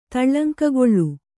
♪ taḷḷaŋkagoḷḷu